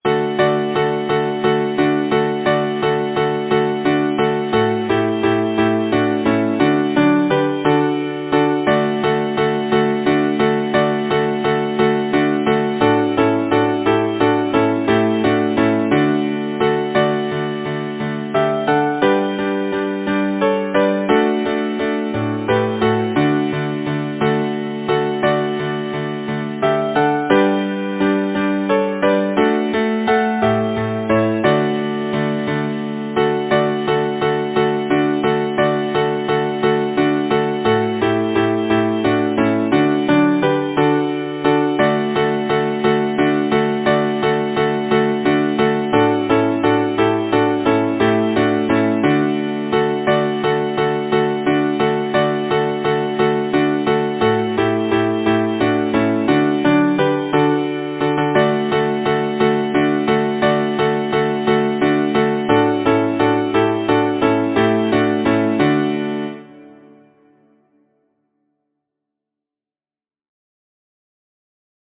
Title: Come down in the meadow Composer: Solomon W. Straub Lyricist: Eben Eugene Rexford Number of voices: 4vv Voicing: SATB Genre: Secular, Partsong
Language: English Instruments: A cappella